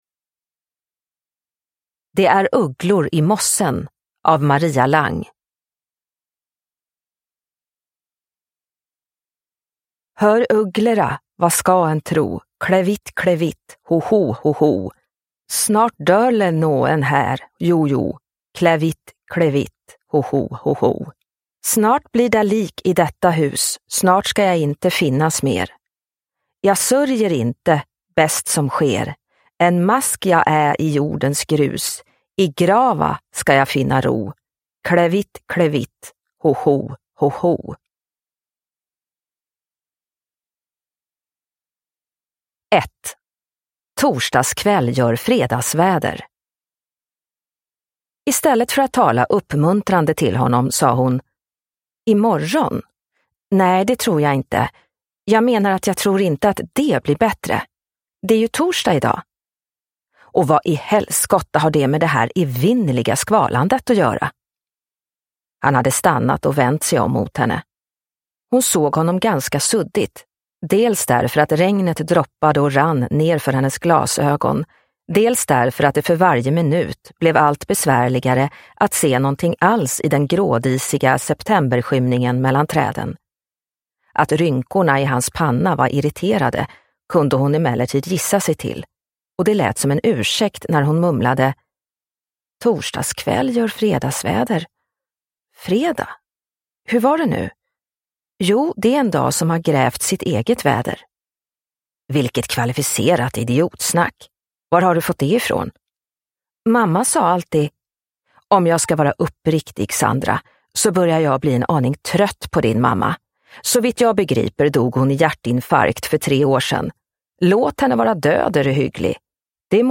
Det är ugglor i mossen – Ljudbok – Laddas ner